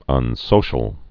(ŭn-sōshəl)